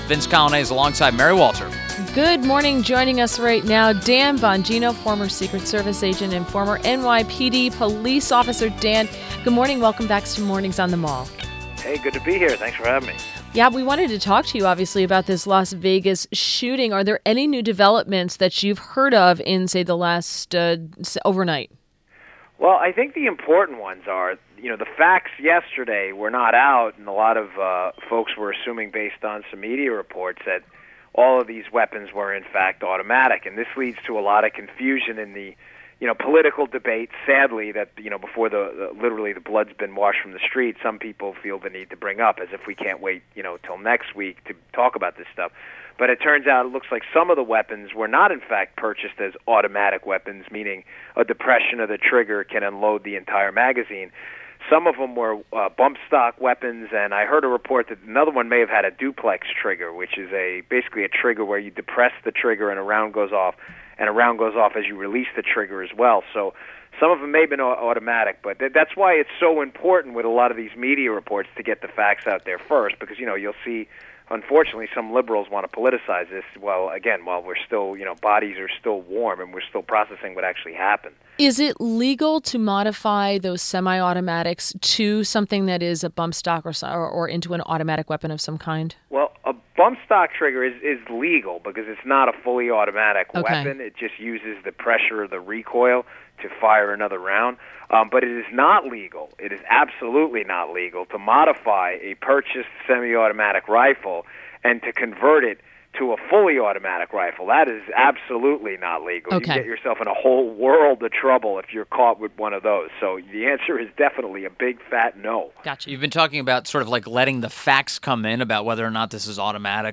INTERVIEW - DAN BONGINO - former Secret Service agent and author of new book "Protecting the President" AND Bongino worked with the New York City Police Department for four years